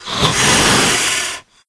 c_wserpent_hit3.wav